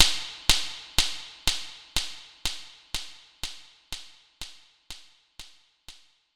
平手打ち/エコー３